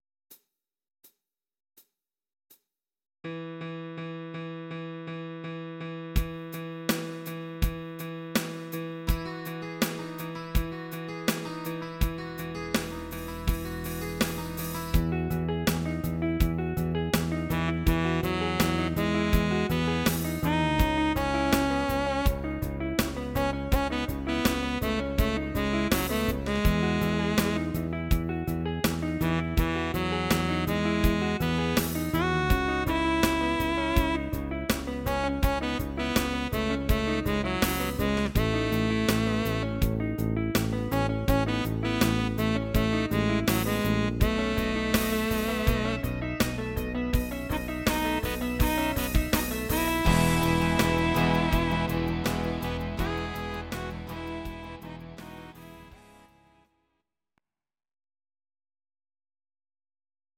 Audio Recordings based on Midi-files
Rock, 2000s